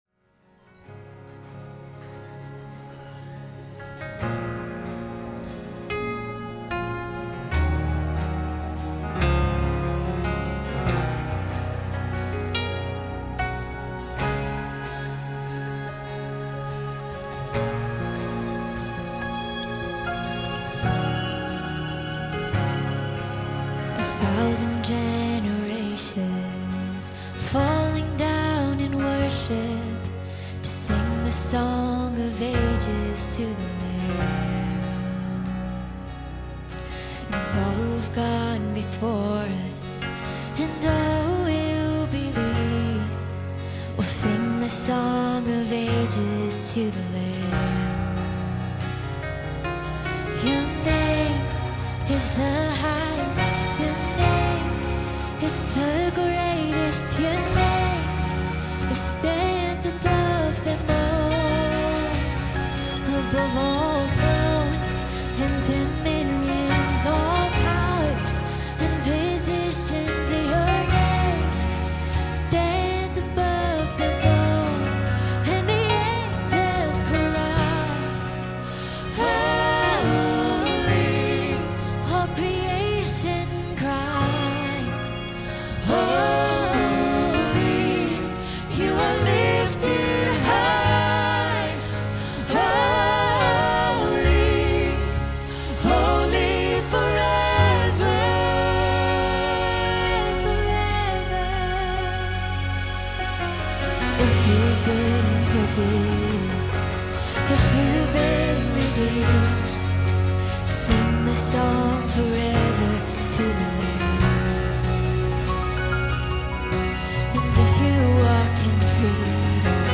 The Mount Church Message Audio